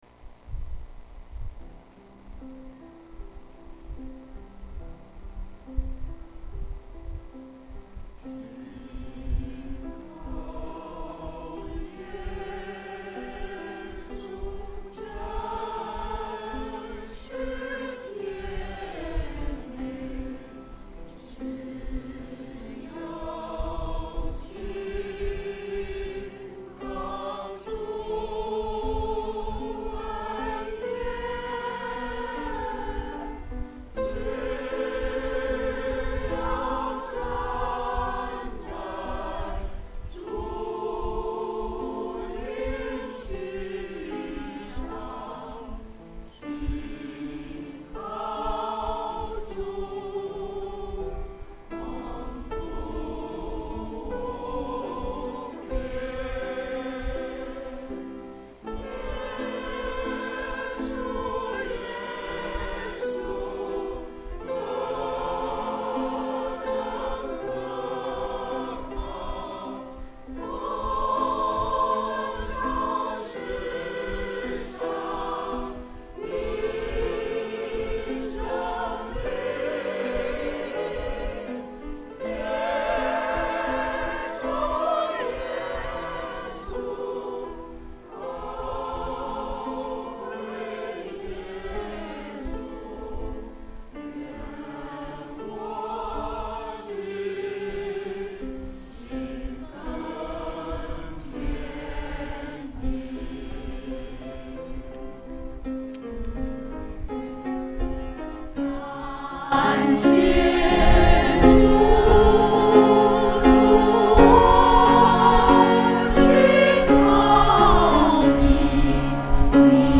• 詩班獻詩